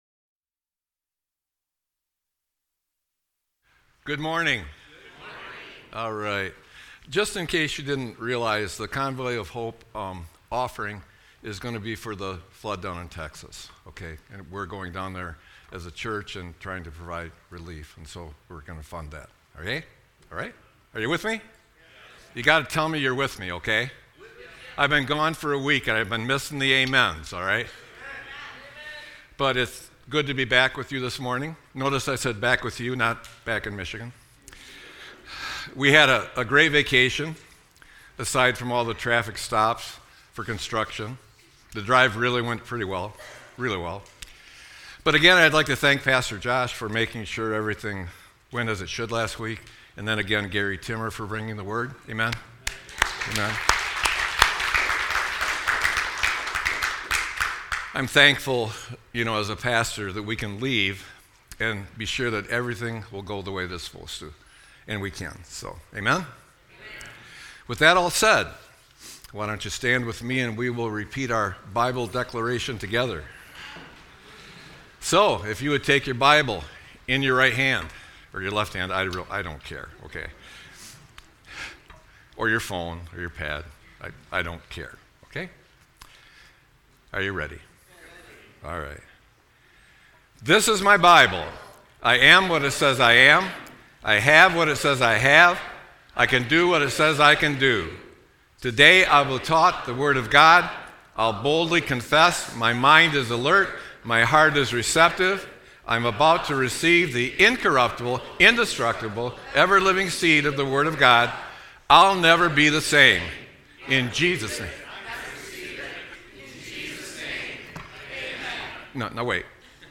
Sermon-7-13-25.mp3